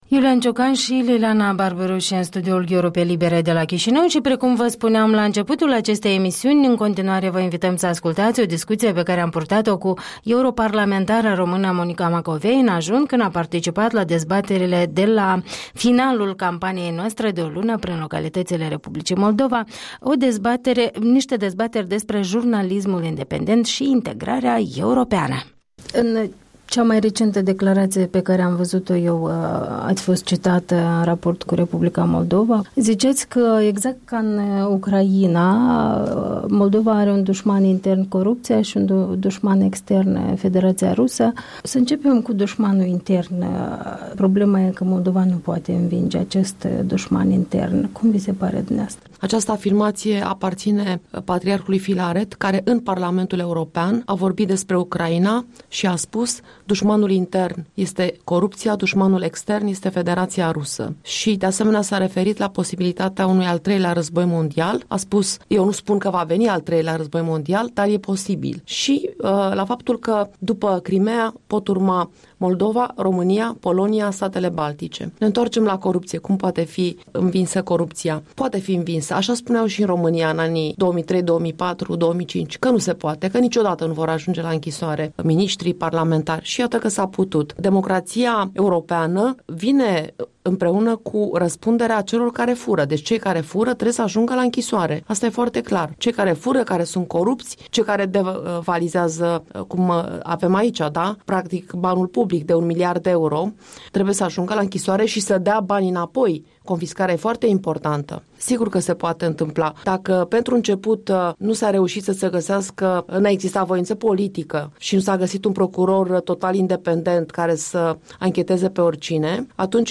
Interviul dimineții cu europarlamentara română.